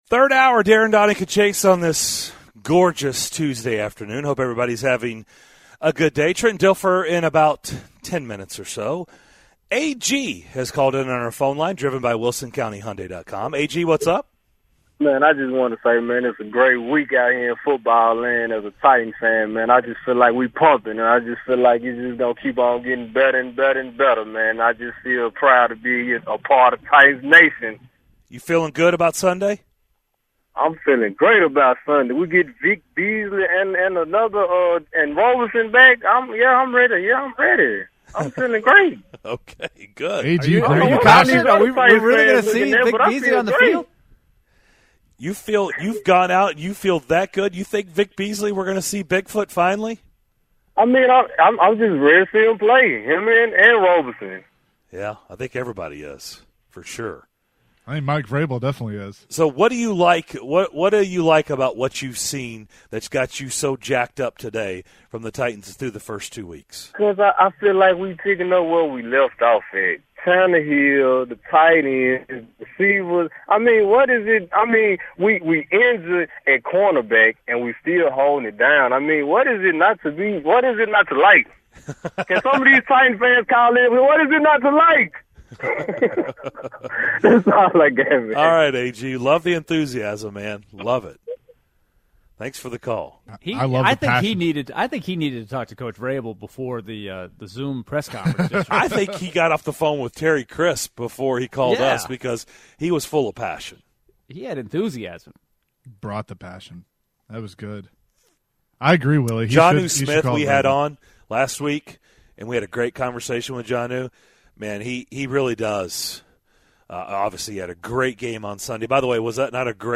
In the third hour of Tuesday's show: callers weigh in on the Titans upcoming matchup with the Vikings, Trent Dilfer joins the show to talk Titans and more!